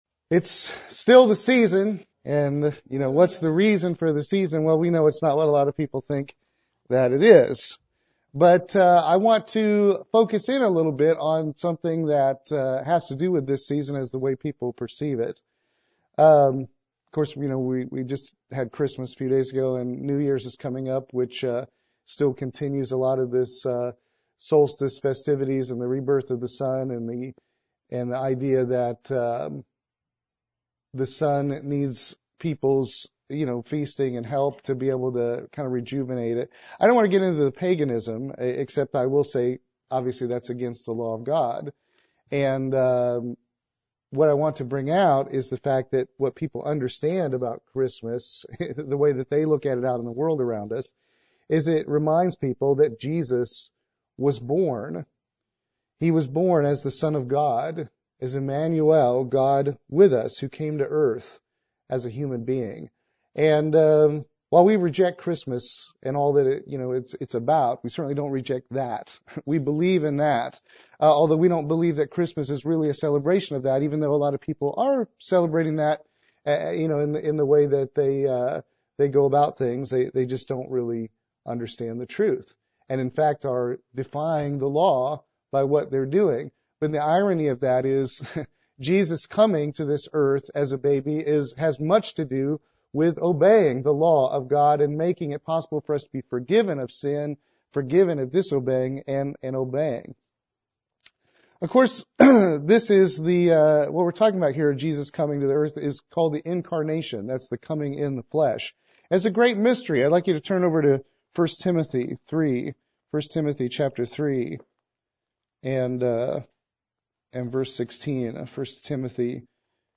Given in Columbia - Fulton, MO
UCG Sermon Studying the bible?